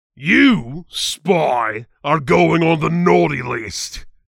Saxton Hale responses ) Extracted with BSPZip from the various official vsh .bsp files.
Licensing This is an audio clip from the game Team Fortress 2 .
Saxton_Hale_Maul_stabbed_01.mp3